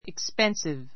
expensive 中 A1 ikspénsiv イ クス ペ ンスィ ヴ 形容詞 （値段が） 高い , 高価な 反対語 cheap , reasonable （安い） expensive clothes expensive clothes 高価な服 Her ring is more expensive than mine.